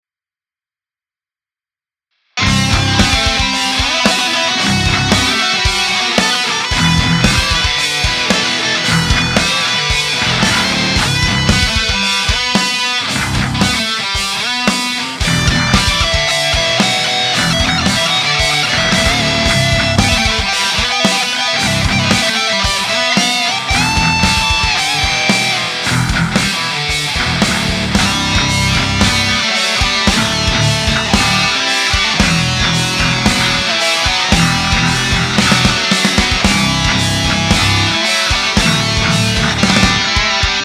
���� � ����� Metalcore \m/